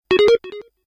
game_start.wav